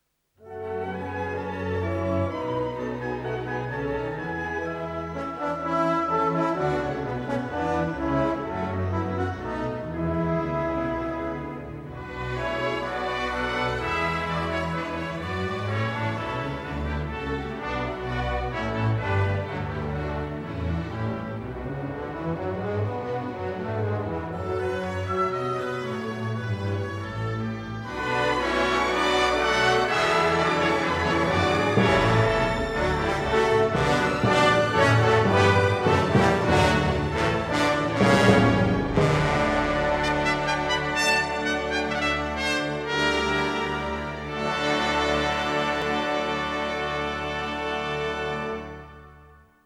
Sintonia instrumental de sortida.